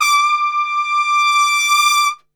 D 4 TRPSWL.wav